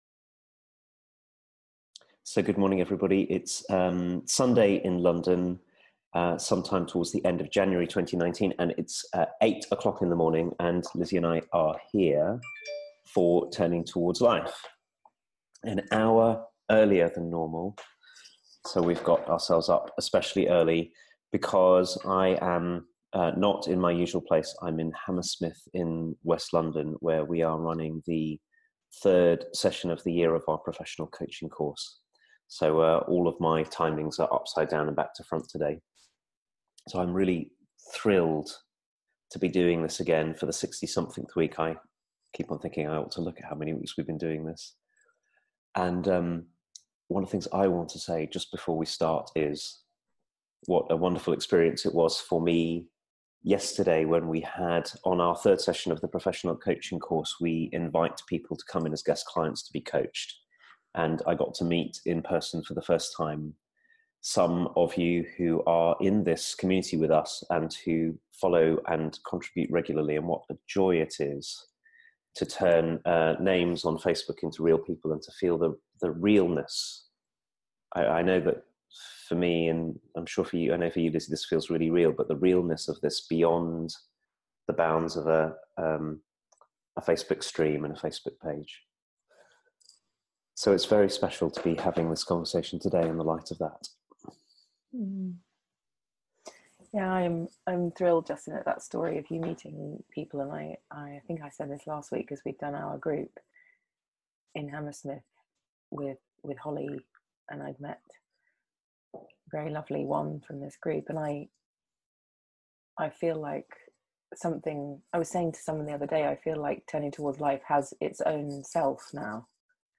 In this conversation we consider together how easily our fear of loss can stop us from loving fully, how love and grief are but two sides of the same coin, and how we might step into both with a clear heart, courage and tenderness. And along the way we find much to be joyful about, and to laugh about.